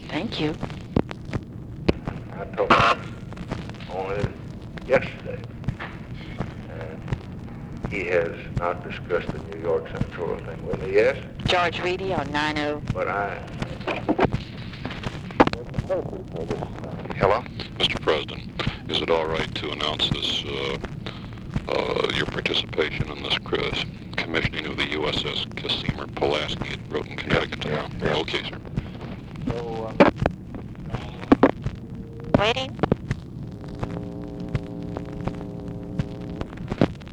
Conversation with GEORGE REEDY and OFFICE CONVERSATION, August 13, 1964
Secret White House Tapes